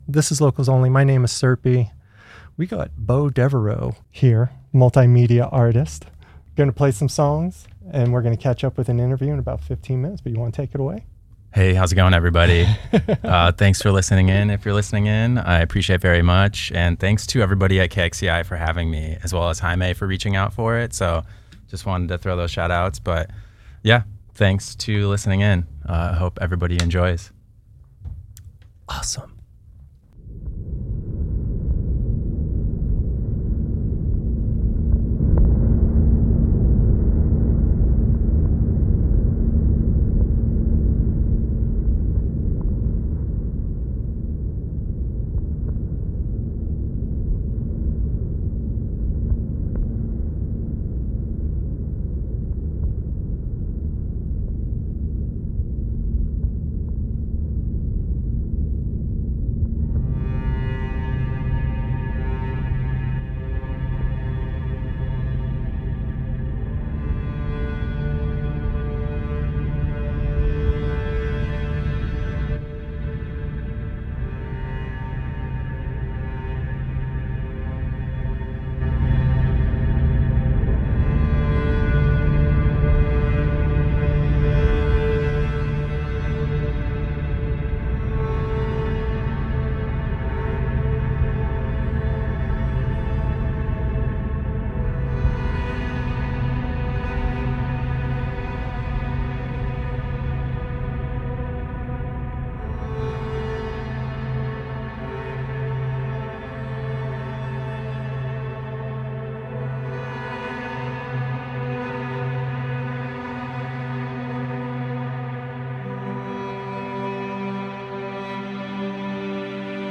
Listen to the live performance + interview here!
experimental expressionist